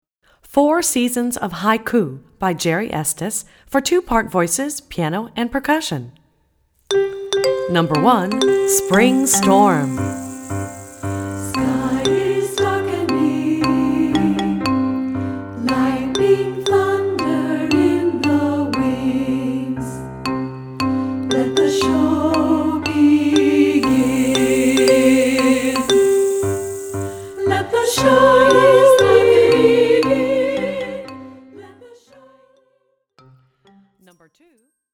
Voicing: Accompaniment CD